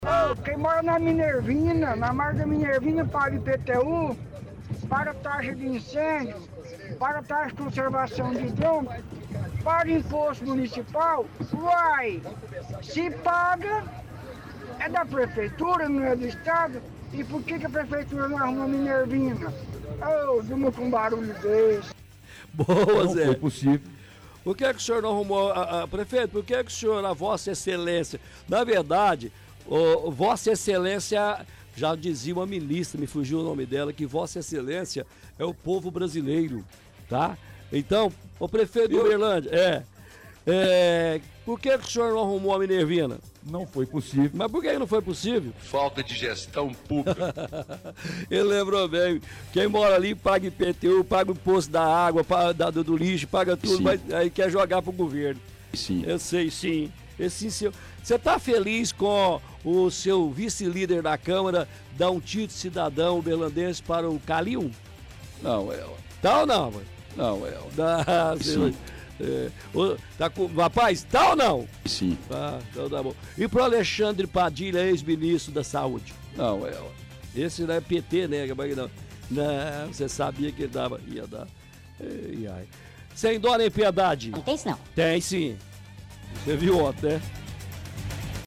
– Ouvinte diz o cidadão paga IPTU e de incêndio para o município de Uberlândia ao questionar porque a responsabilidade da Minervina não seria também do município.